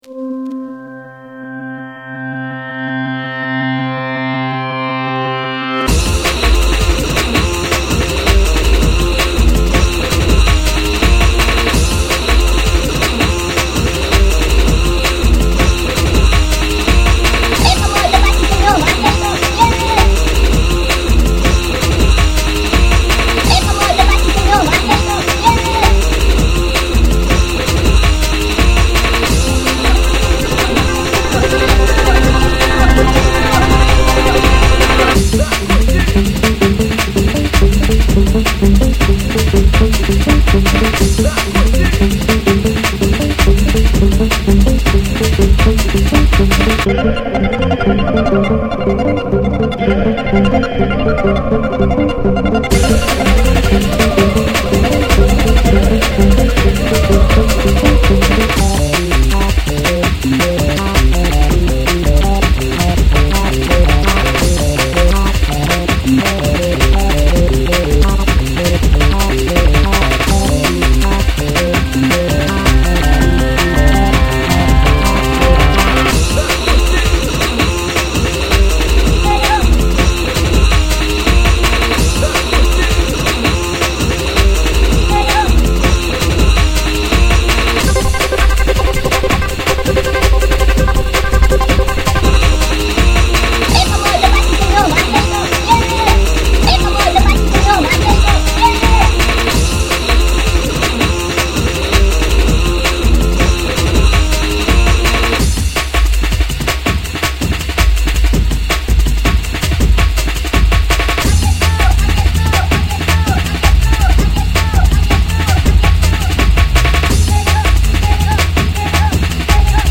смесь различных направлений в тяжелой музыке.